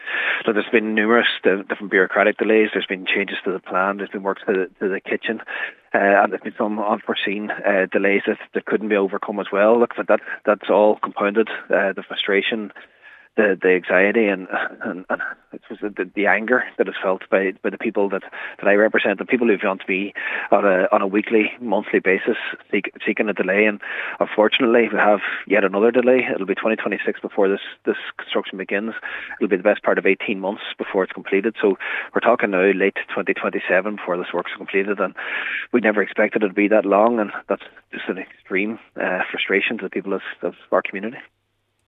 Local Cllr Jack Murray has explained the how some of the delays have occurred and the frustration of the community at the news: